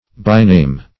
By-name \By"-name`\, n.